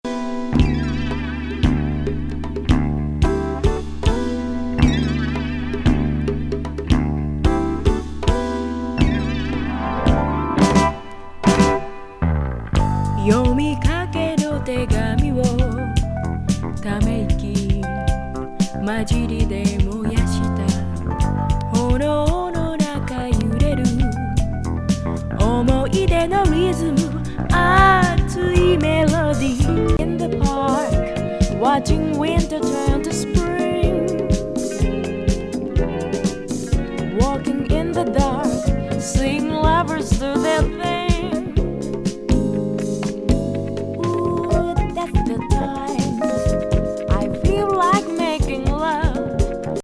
シンセも気持ちよい